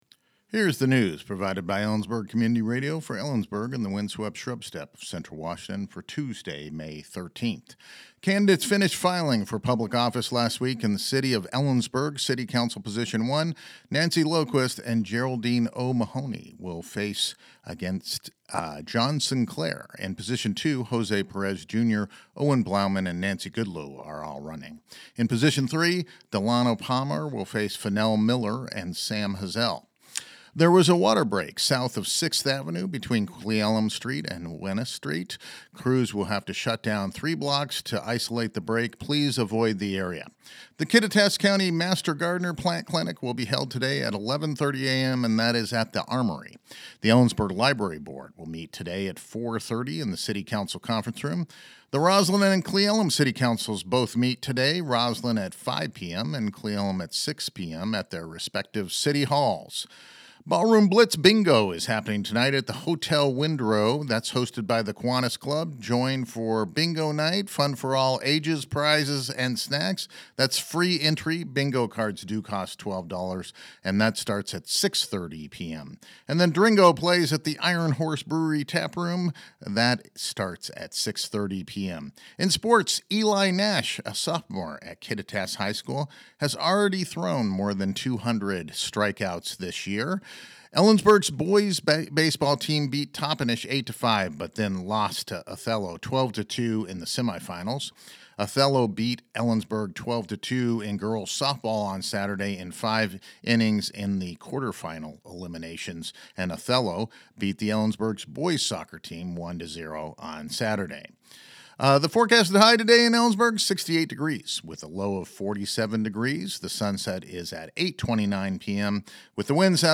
LISTEN TO THE NEWS HERE NEWS Candidates finished filing for public office last week.